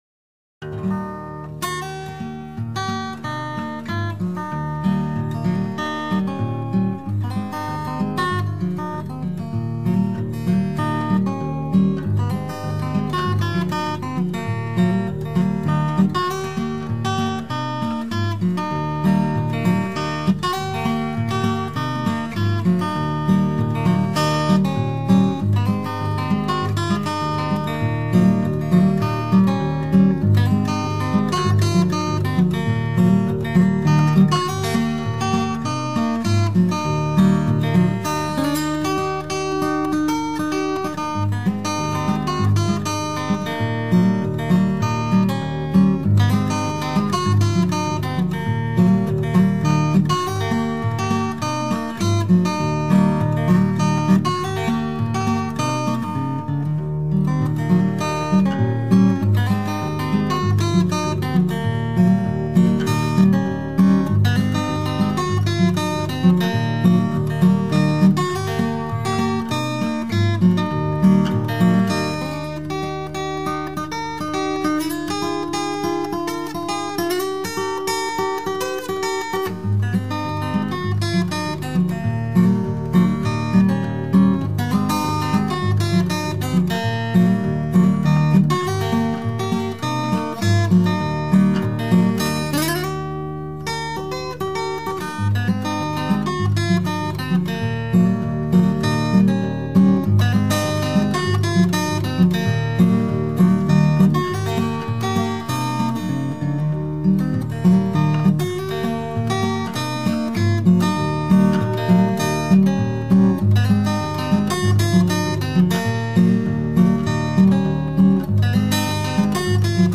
The intro jumps straight into the turnaround: